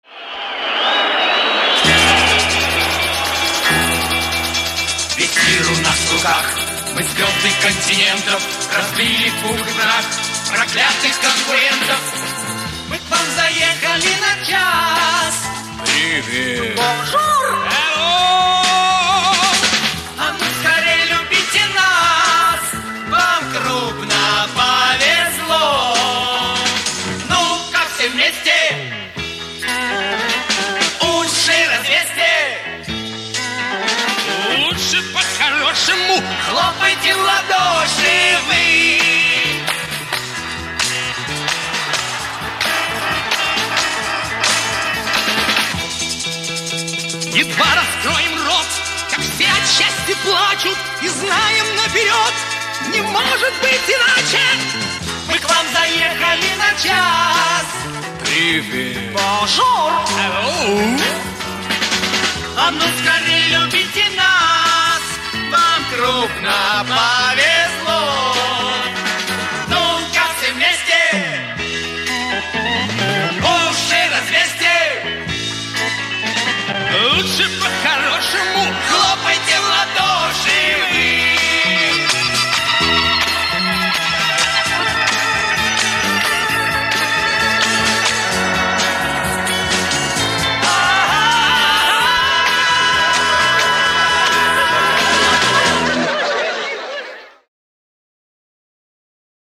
эстрадный хит